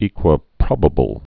(ēkwə-prŏbə-bəl, ĕkwə-)